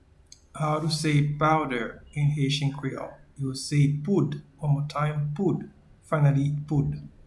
Pronunciation and Transcript:
Powder-in-Haitian-Creole-Poud.mp3